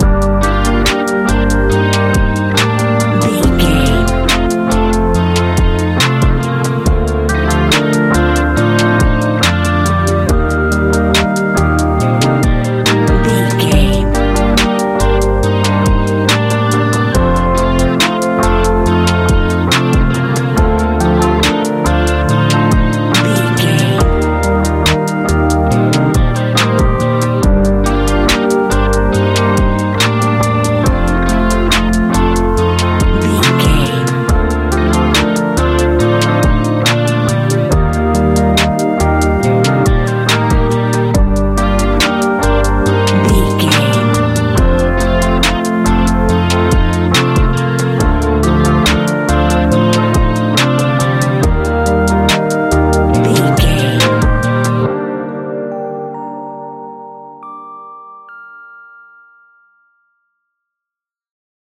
Ionian/Major
A♯
laid back
Lounge
sparse
new age
chilled electronica
ambient
atmospheric